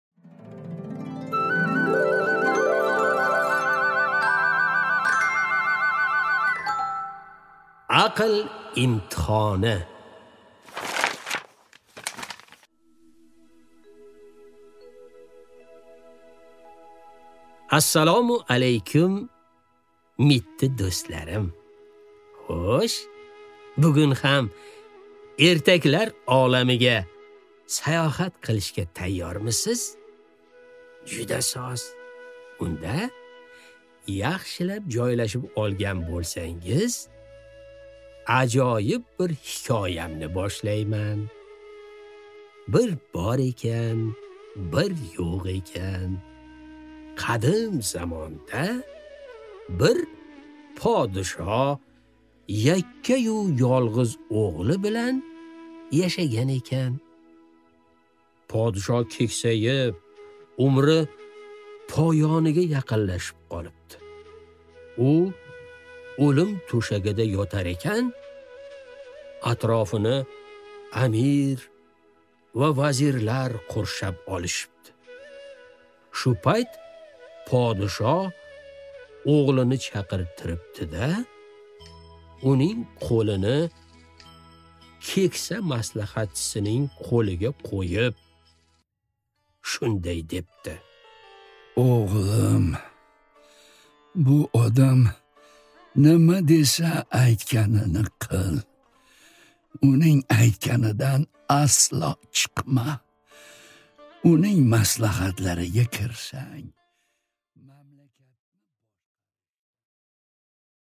Аудиокнига Aql imtihoni | Библиотека аудиокниг